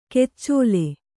♪ keccōle